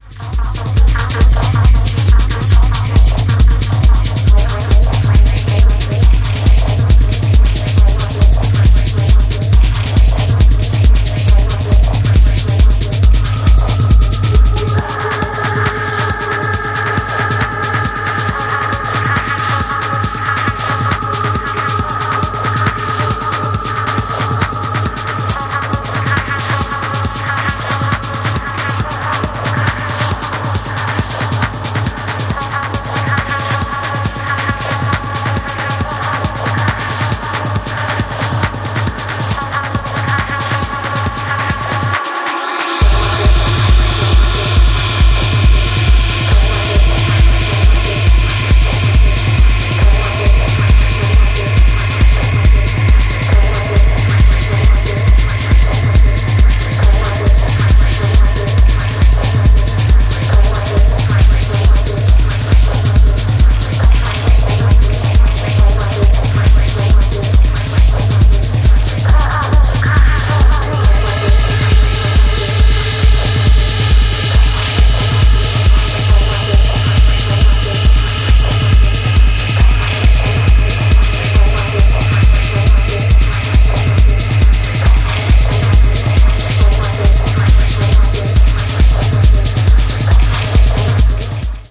four hard-hitting techno cuts